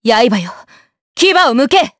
Seria-Vox_Skill2_jp.wav